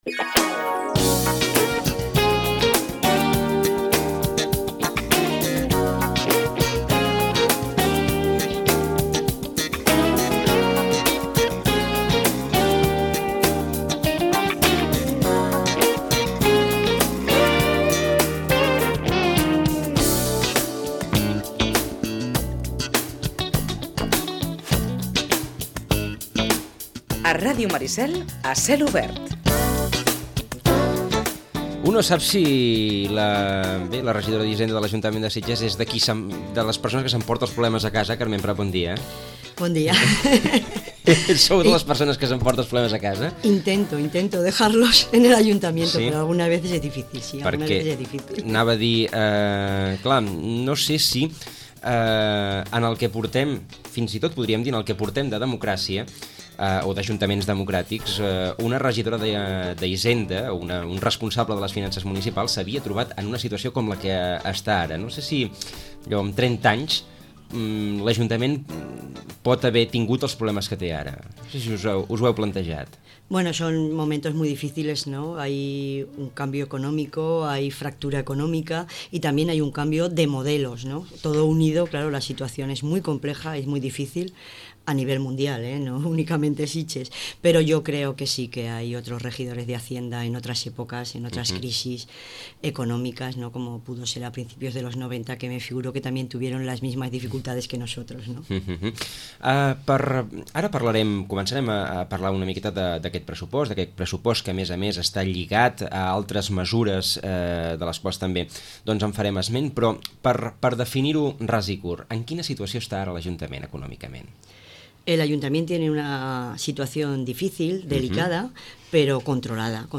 L’Ajuntament de Sitges afronta una situació econòmica difícil, que l’ha motivat a pactar un nou Pla de Sanejament amb la Generalitat. En aquest marc, condicionat per la crisi global, s’ha aprovat un pressupost de contenció. Ho explica la regidora d’Hisenda, Carmen Prat.